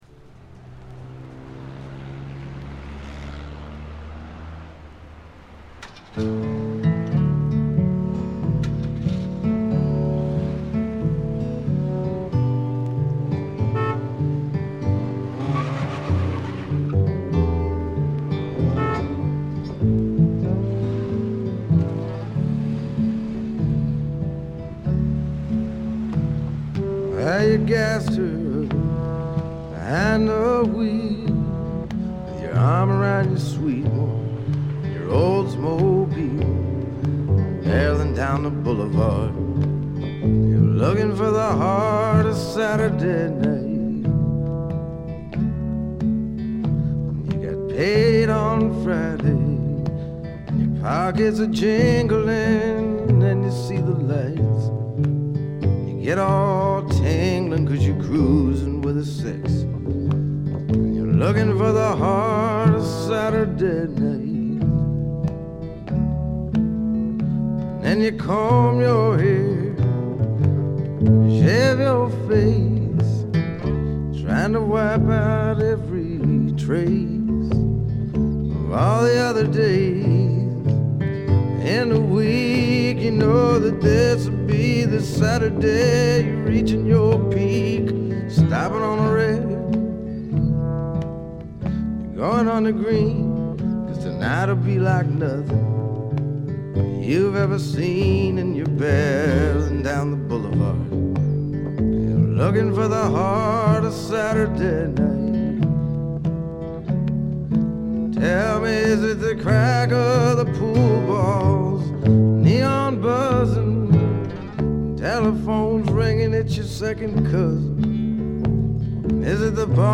軽微なチリプチ程度。
メランコリックでぞっとするほど美しい、初期の名作中の名作です。
試聴曲は現品からの取り込み音源です。